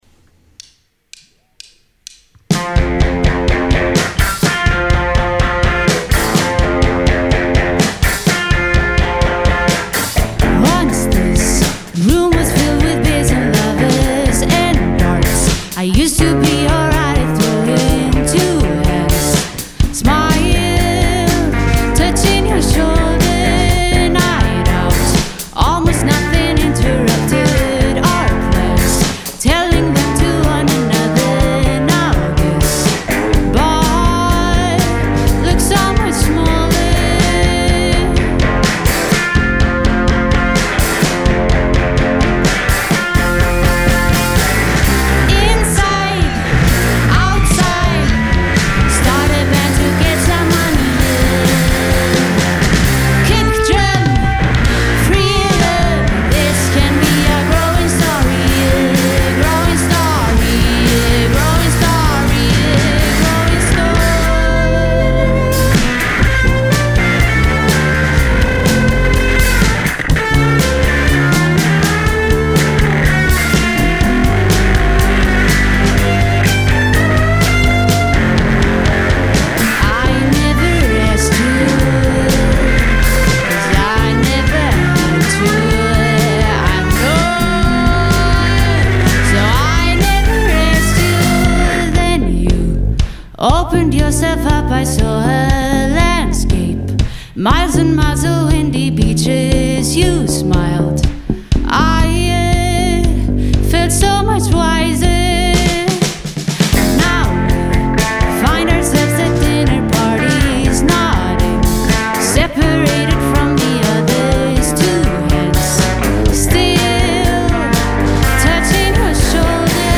reflective and life-affirming post-punk
four-piece rock instrumentation
slightly chaotic, always exhilarating sound